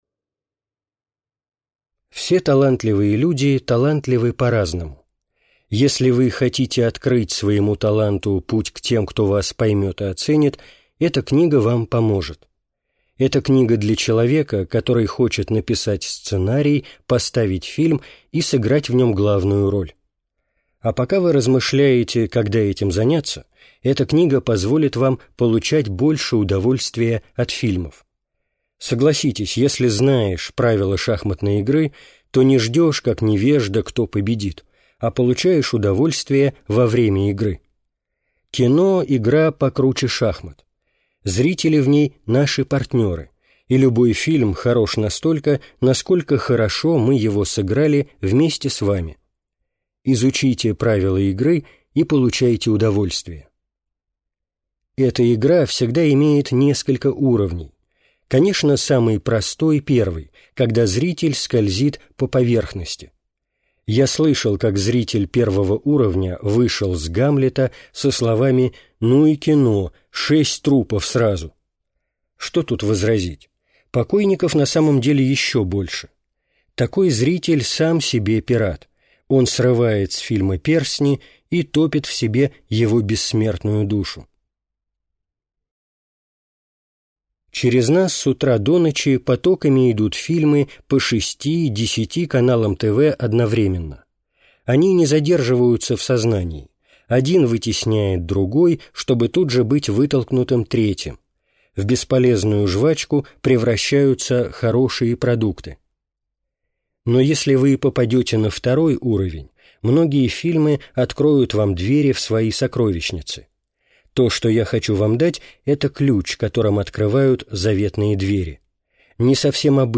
Аудиокнига «Кино между адом и раем» в интернет-магазине КнигоПоиск ✅ Публицистика в аудиоформате ✅ Скачать Кино между адом и раем в mp3 или слушать онлайн